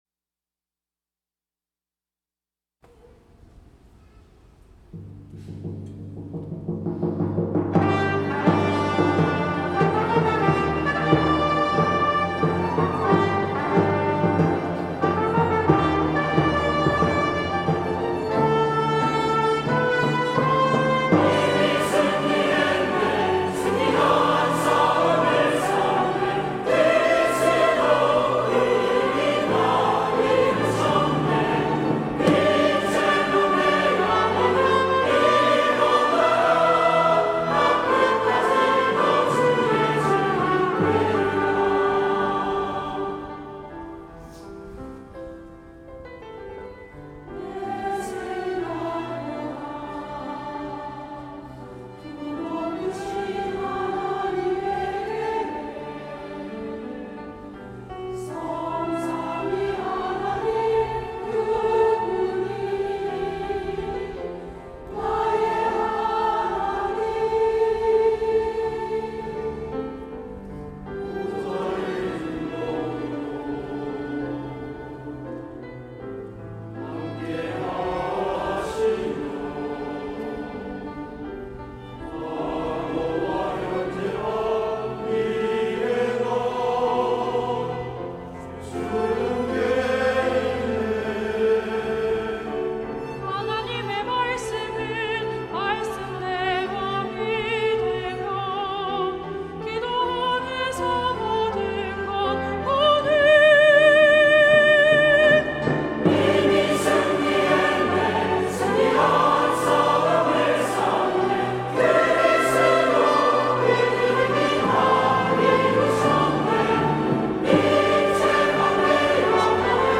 2부 찬양대